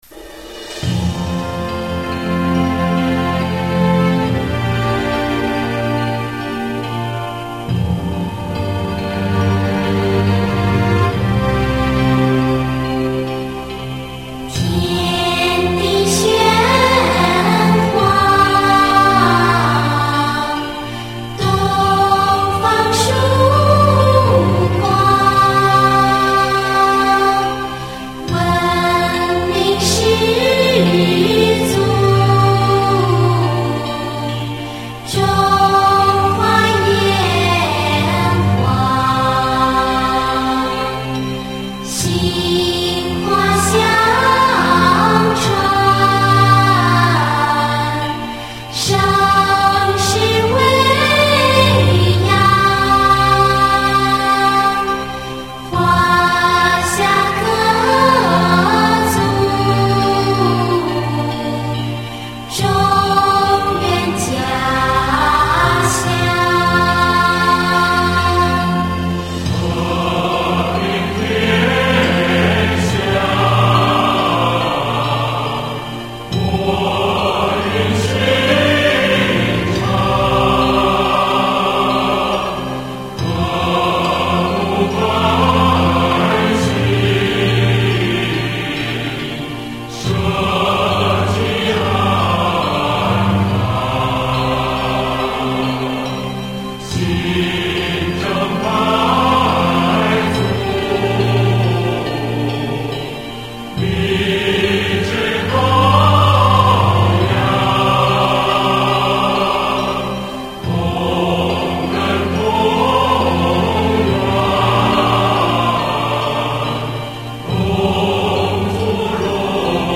合唱版